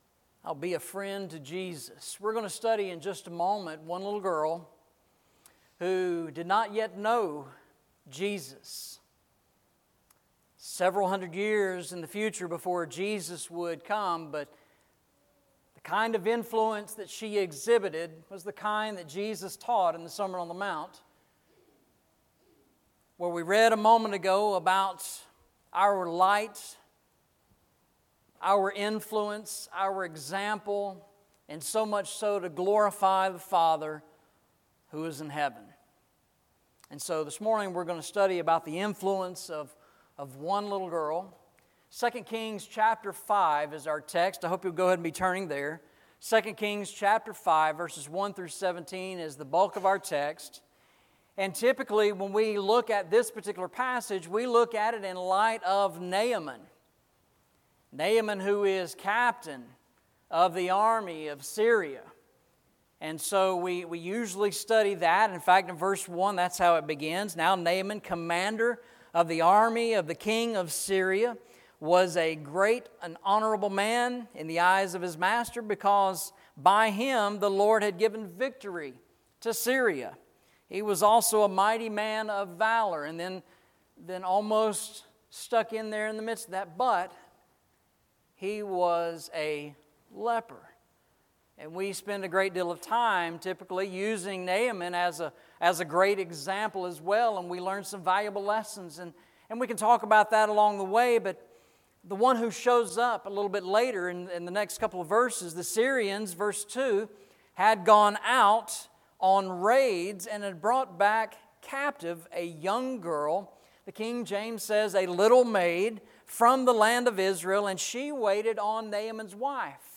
Series: Eastside Sermons
Matthew 5:13-16 Service Type: Sunday Morning « Ye Ought to Be Teachers Walking Through the Bible